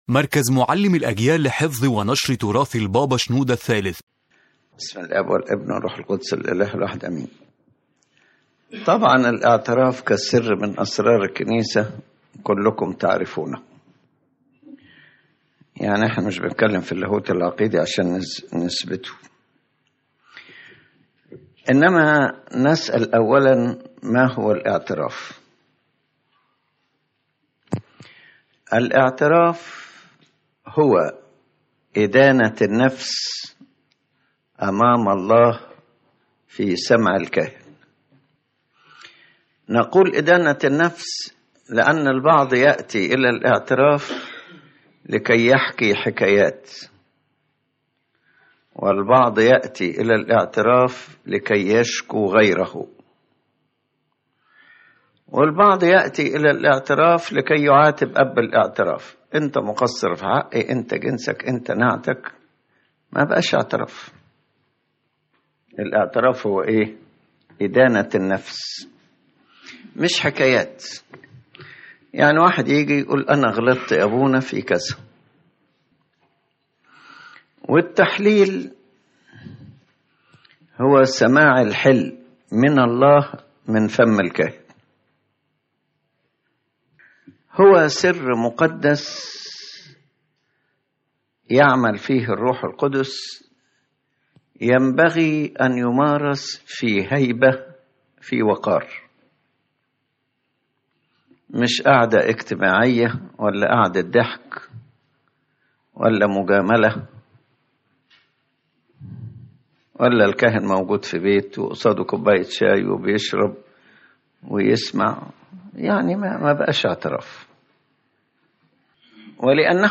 ⬇ تحميل المحاضرة أولًا: جوهر سر الاعتراف يوضح قداسة البابا شنوده الثالث أن الاعتراف هو إدانة النفس أمام الله في سمع الكاهن، وليس مجرد حكايات أو شكوى من الآخرين.